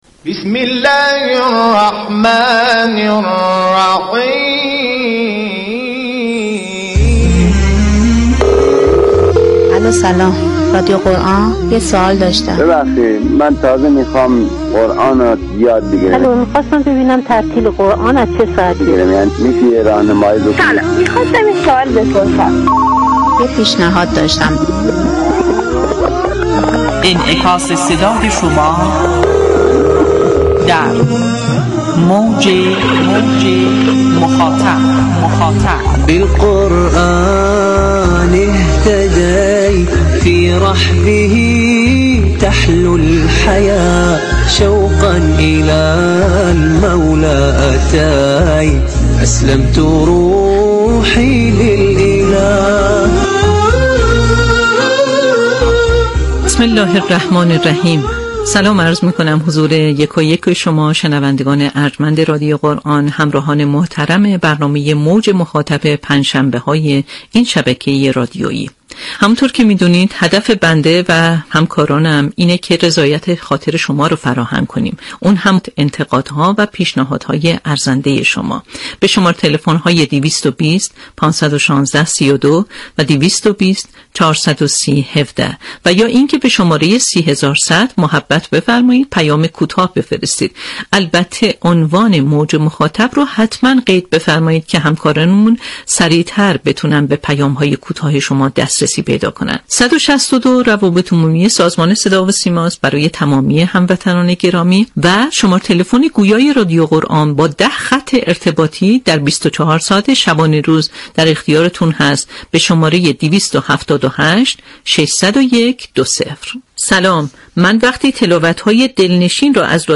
همچنین گفت‌وگو با مدیران گروه‌های برنامه‌ساز و اطلاع‌رسانی برنامه‌های جدید در ایام و مناسبت‌های مختلف، ارتباط با گزارشگران مراكز شهرستان‌ها و اطلاع از برنامه‌های مراكز مختلف از دیگر بخش‌های برنامه خواهد بود.